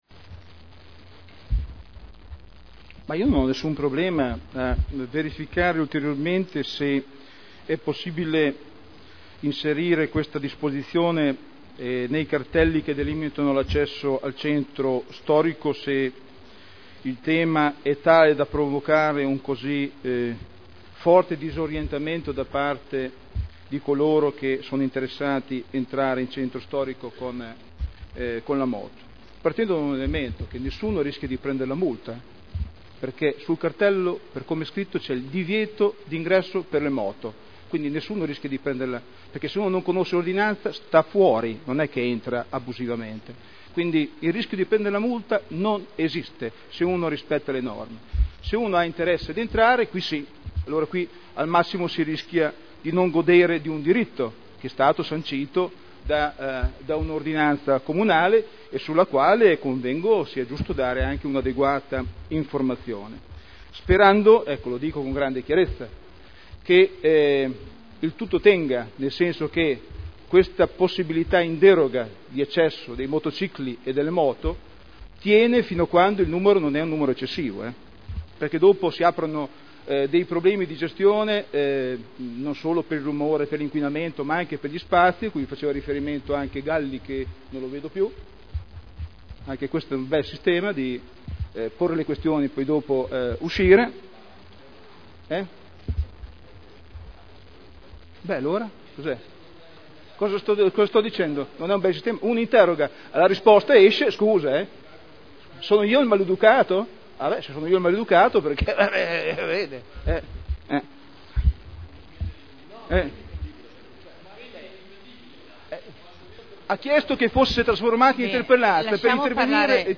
Seduta del 09/11/2009. Confusione nell'accesso dei motocicli alla zona ZTL